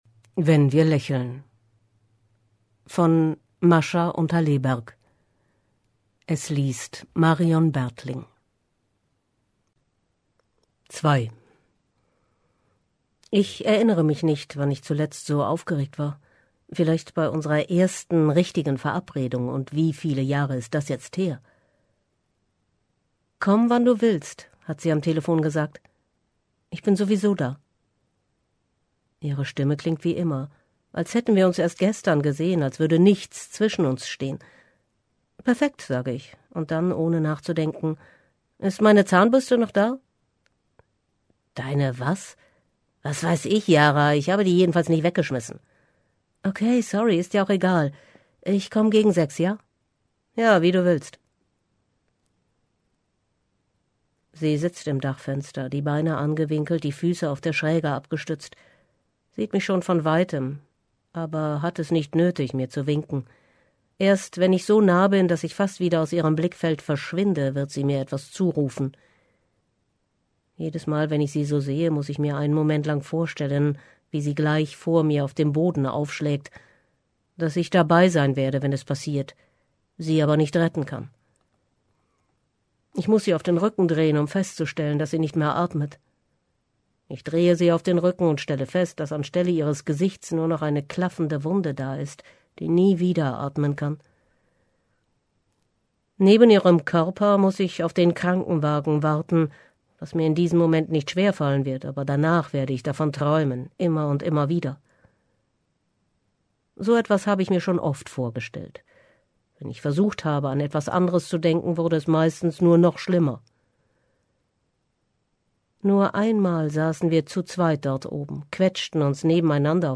liest diesen Debütroman um eine Frauenfreundschaft, die nicht perfekt, aber stark ist: